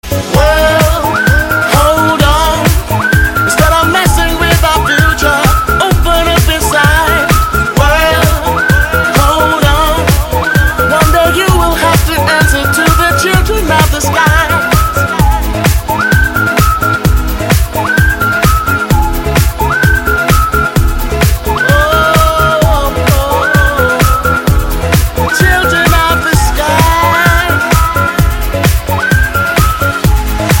свист
зажигательные
веселые